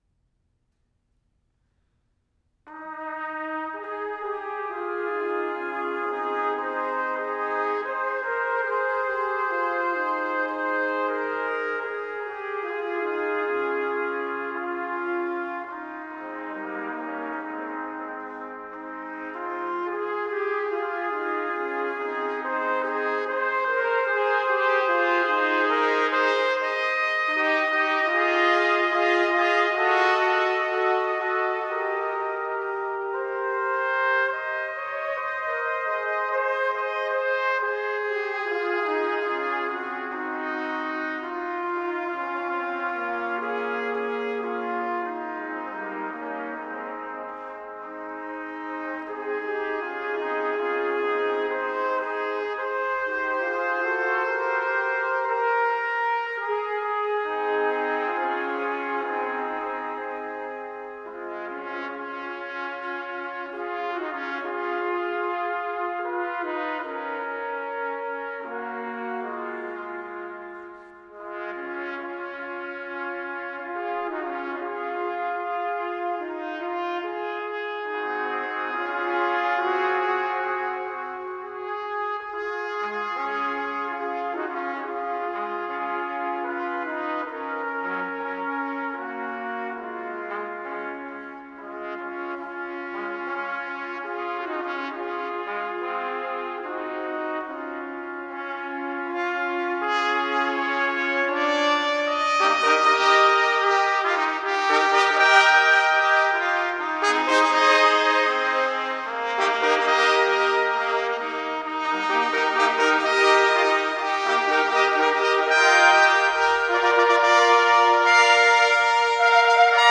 Voicing: Trumpet Quartet